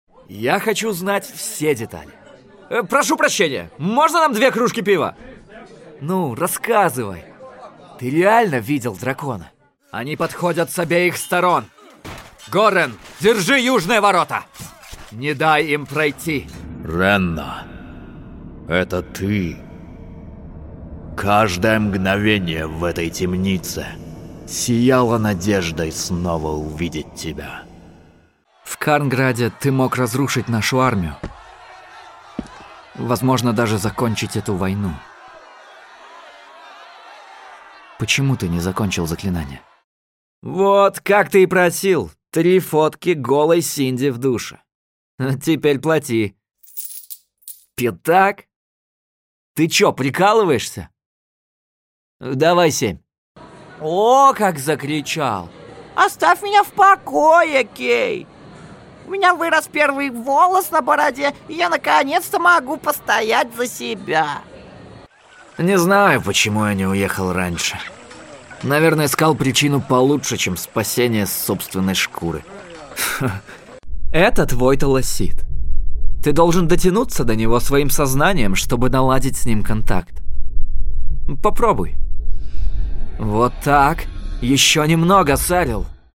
Домашняя студия: Микрофон Neumann TLM 103 Звуковая Карта Audient iD22 DAW Adobe Audition
Певческий голос Баритон